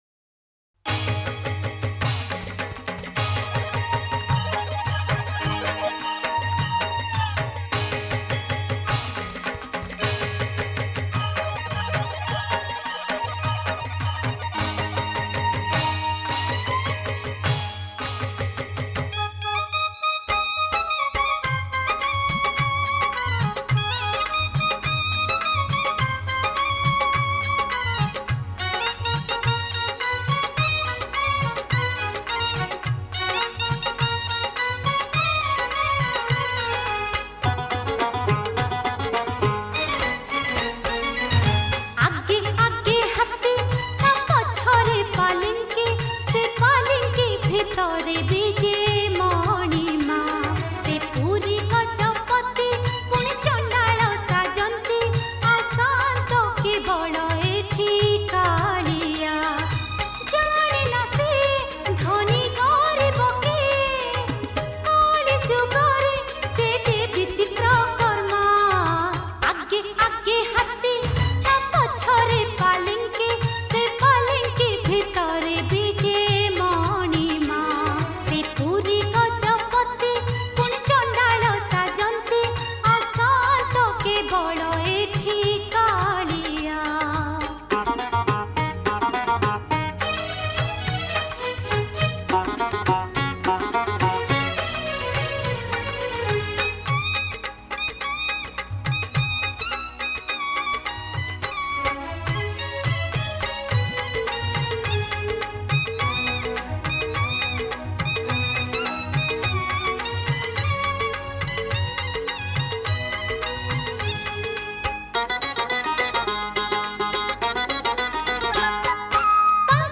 Jagannath Bhajan Songs